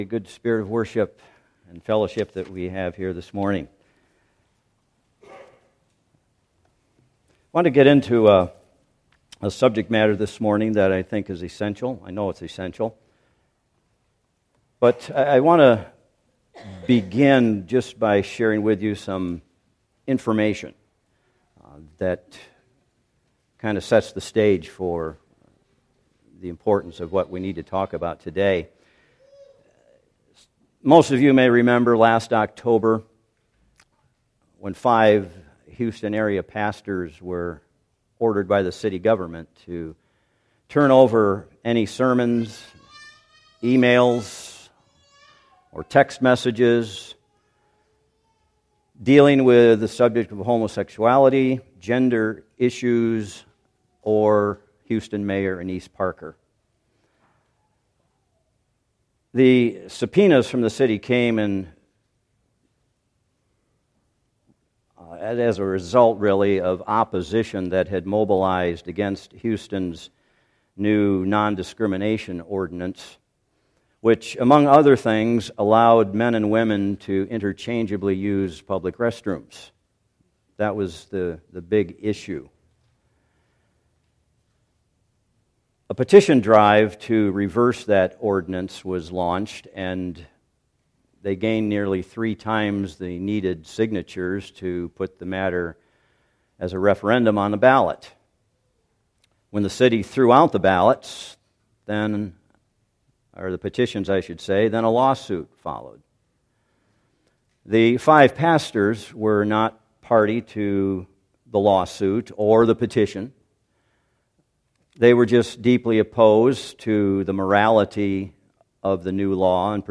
Passage: Luke 18:1-8 Service Type: Worship Service